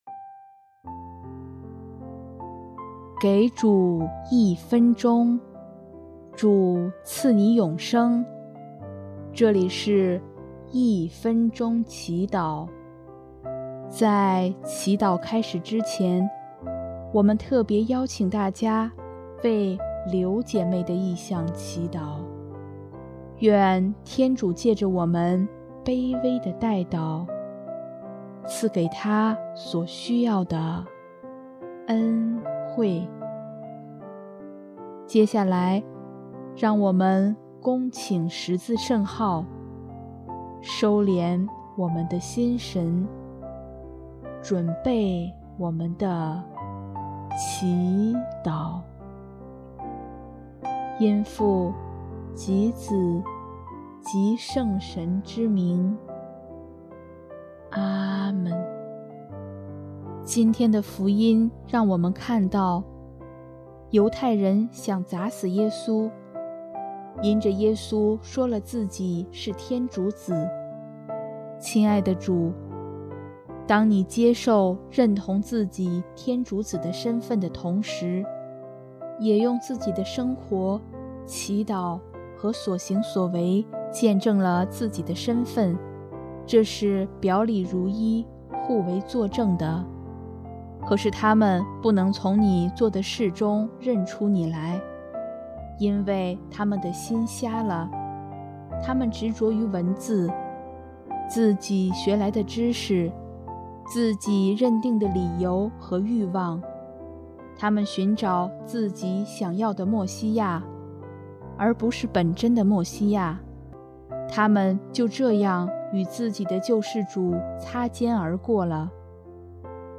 音乐 ：第四届华语圣歌大赛参赛歌曲《慢慢长路》